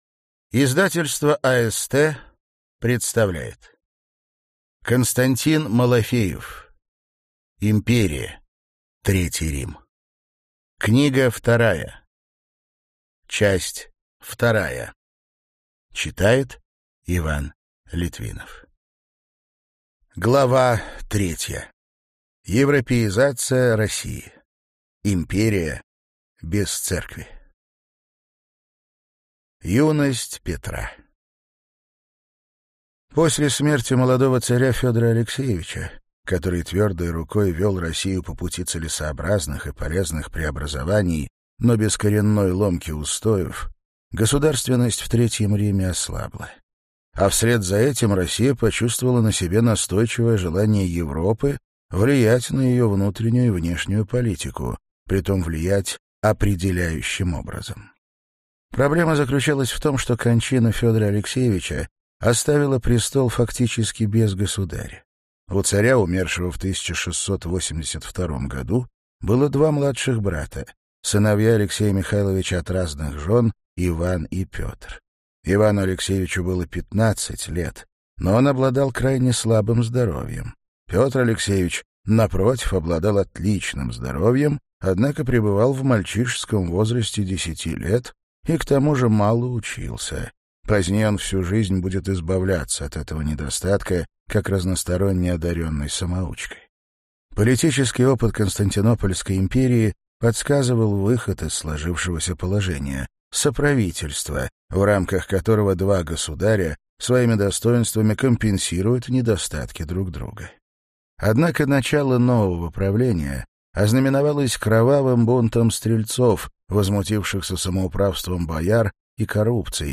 Аудиокнига Империя. Третий Рим. Книга 2. Часть 2 | Библиотека аудиокниг